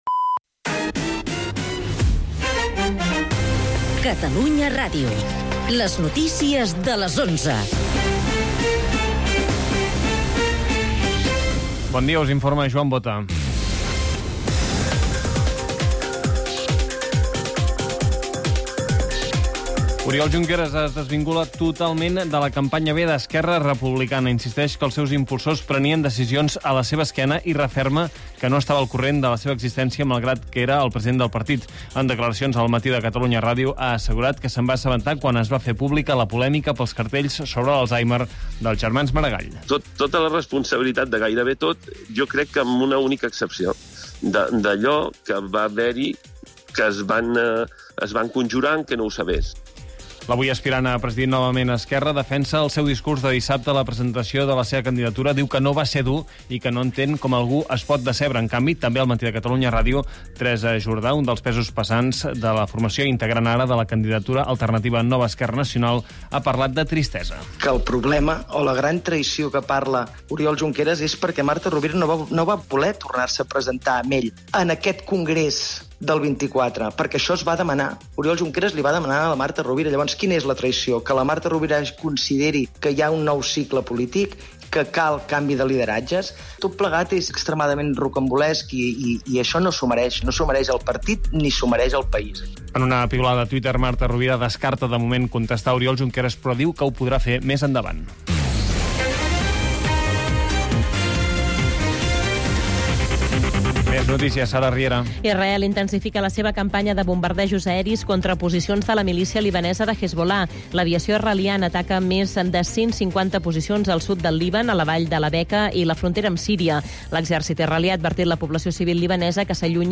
El mat, d'11 a 12 h (entrevista i humor) - 23/09/2024